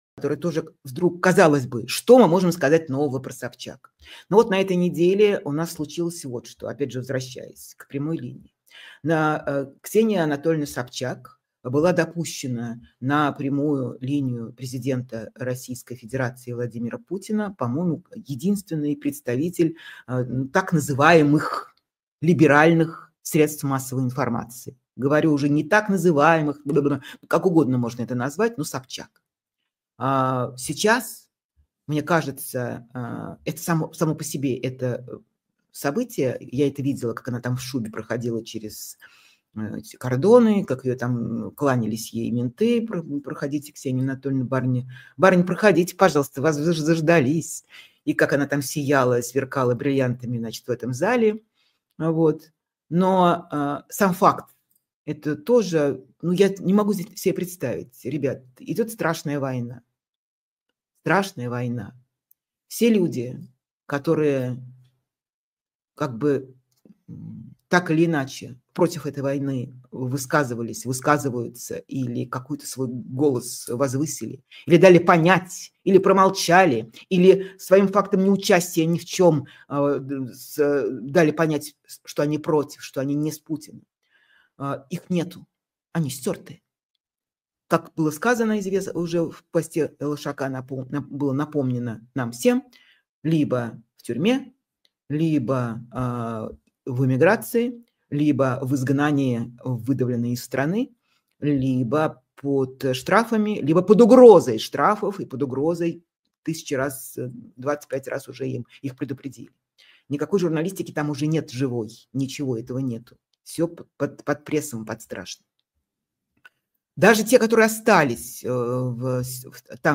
Фргамент эфира от 24.12